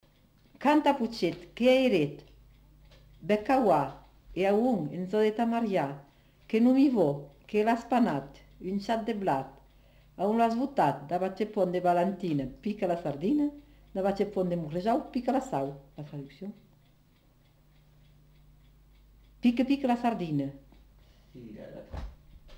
Aire culturelle : Comminges
Lieu : Cathervielle
Genre : forme brève
Type de voix : voix de femme
Production du son : récité
Classification : mimologisme